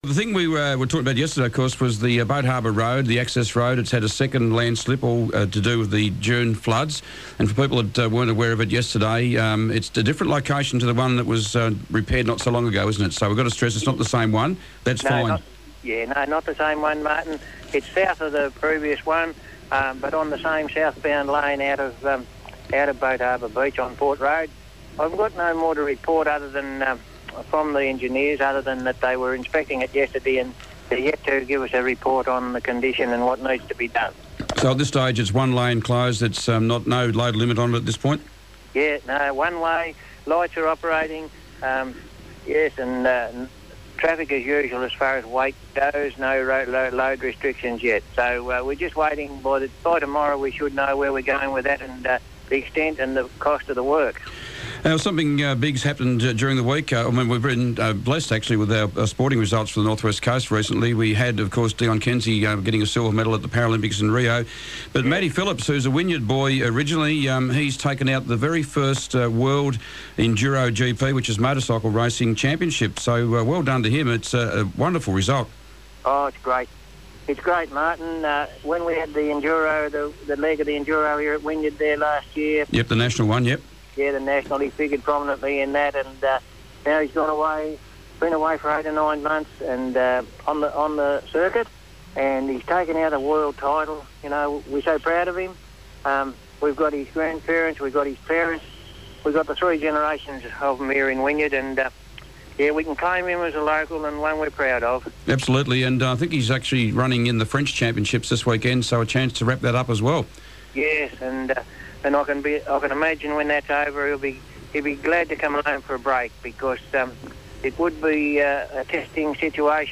Waratah-Wynyard Mayor Robby Walsh was today's Mayor on the Air.